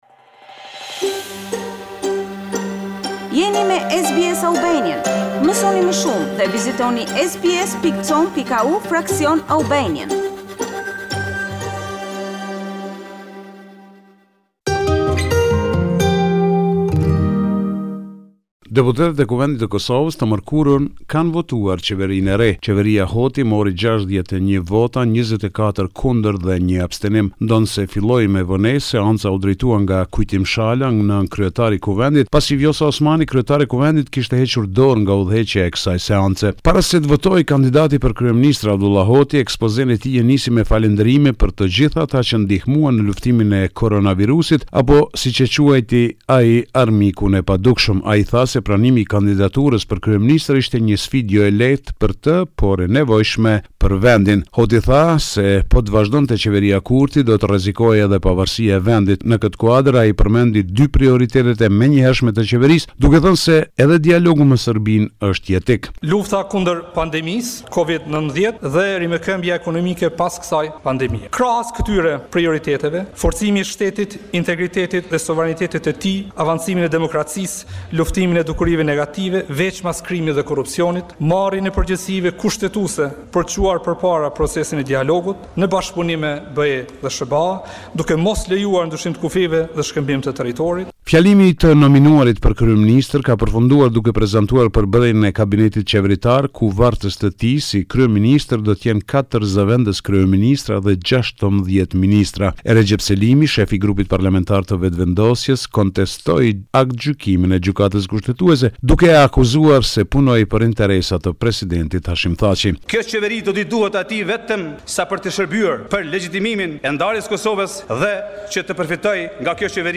This is a report summarising the latest developments in news and current affairs in Kosovo.